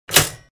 Toaster
Toaster.wav